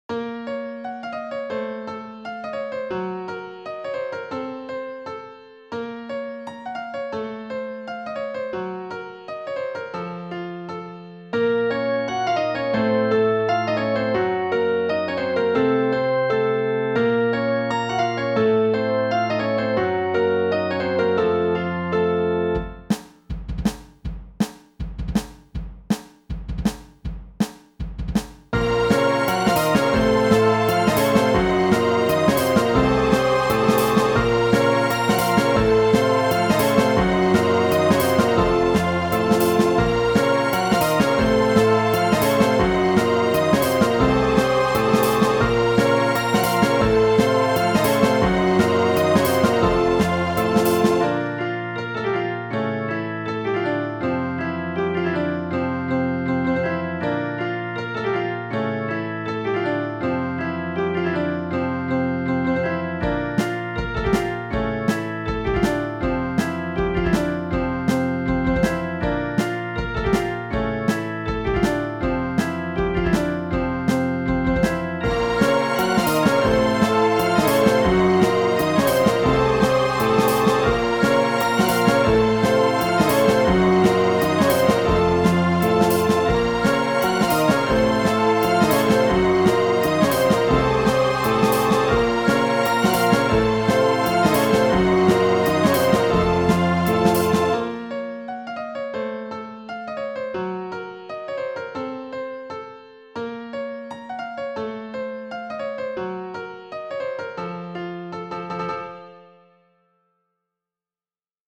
It ends with a D major chord, sure, might even end with a whole D major section.